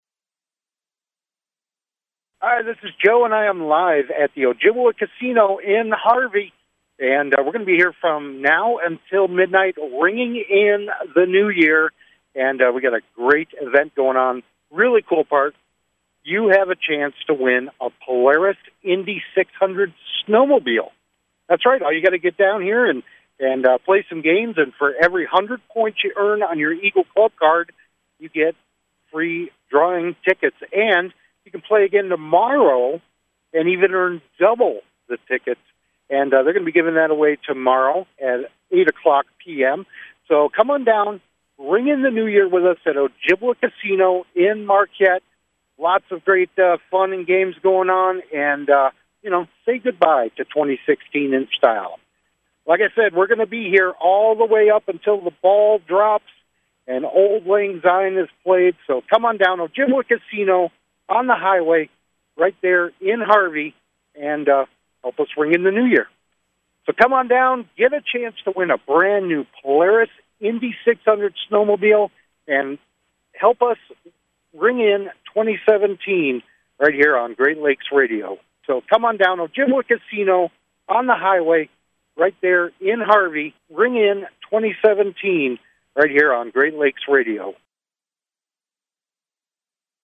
New Years Eve at Ojibwa Casino!
live from the casino where everyone was having a good time at the 40’s themed event.